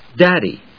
/dˈædi(米国英語), ˈdædi:(英国英語)/